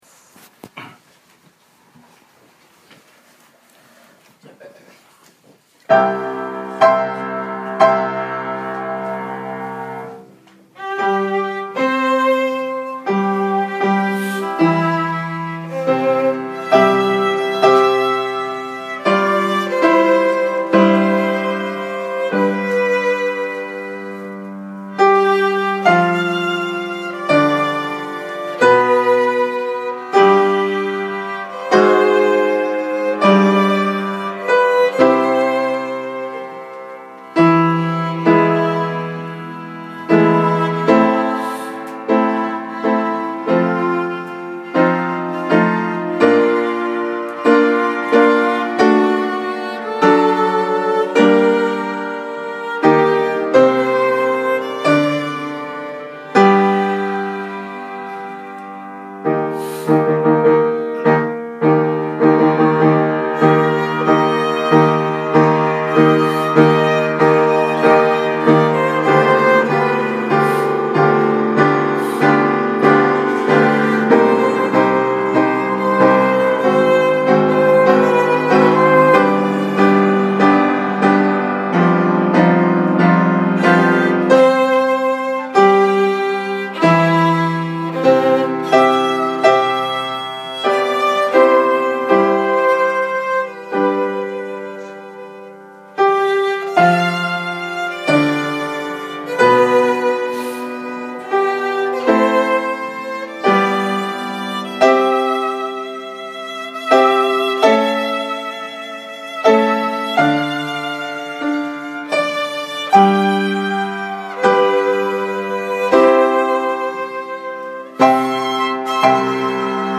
2014年 1月 1日（水） 元日礼拝説教『天の窓を開きなさい』